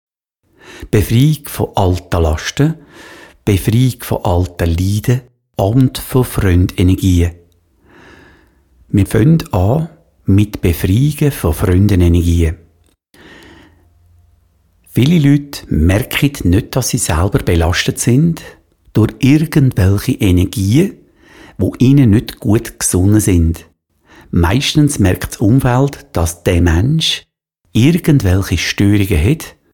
Diese mp3-Download-Datei enthält Anleitungen zum Ablösen von Fremdleiden und Erdmeditationen.